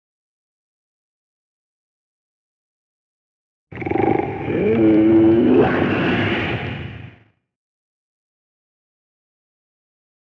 Rugido Puma
rugido.mp3